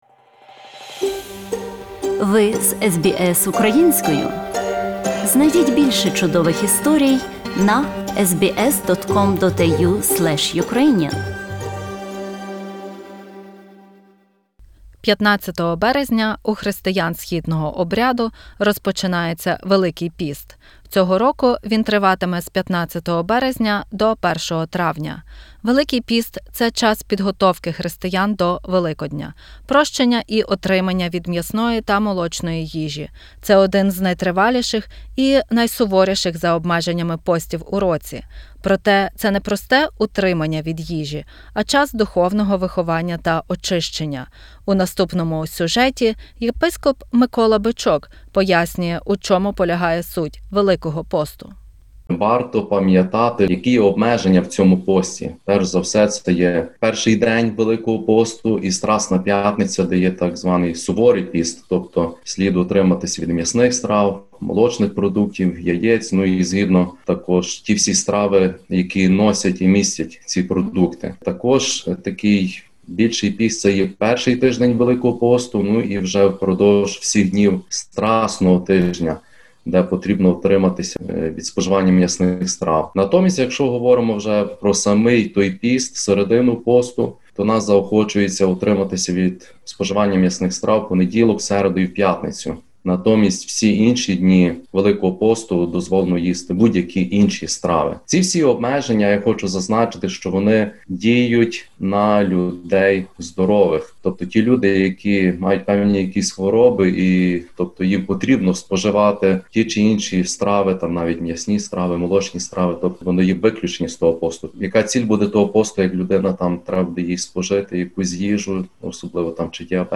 Єпископ УГКЦ Микола Бичок пояснює у чому полягає суть Великого посту.